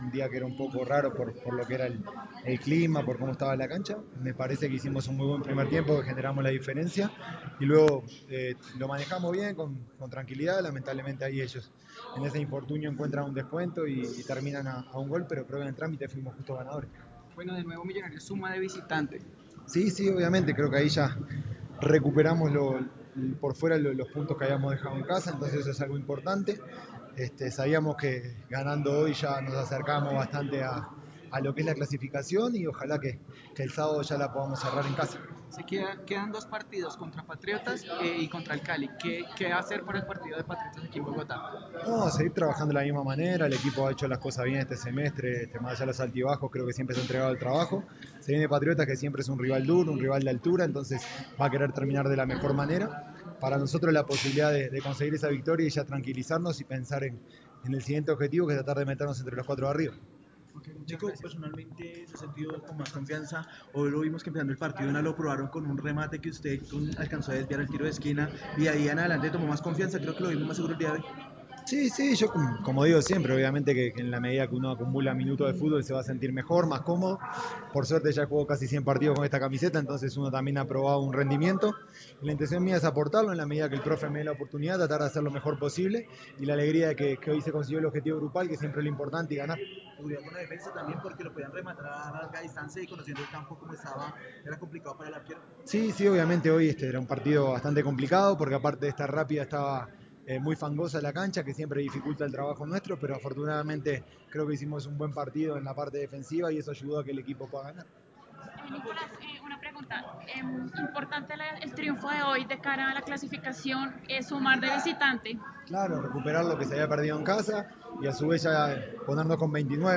El portero azul cerró su entrevista diciendo que el primer objetivo prioritario del equipo era entrar a los ocho, y que la tarea que viene es quedar entre los cuatro primeros para cerrar en casa los cuartos de final, por lo que el equipo irá a buscar los seis puntos que quedan.